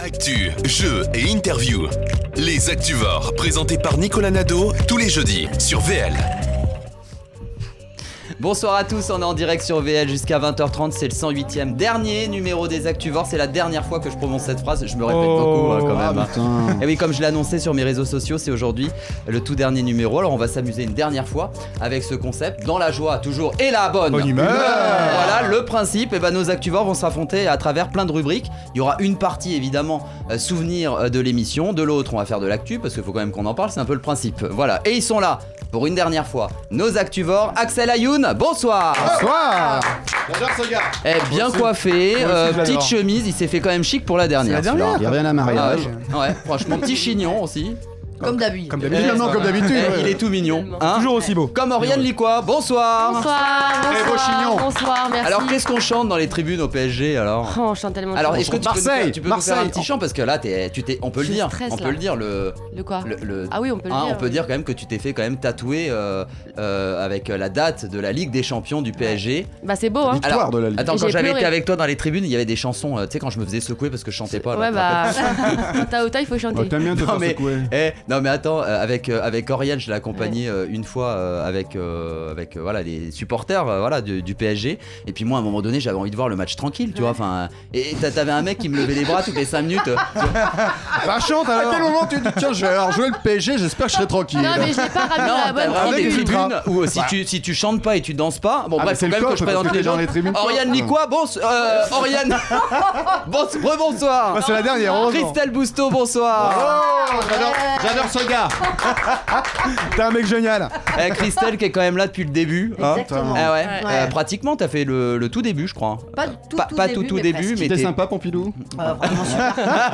Le principe ? Jouer avec l’actualité en marquant des points à travers différentes rubriques. Les chroniqueurs et l’invité(e) s’affrontent pour que l’un d’eux devienne l’Actuvore de la semaine.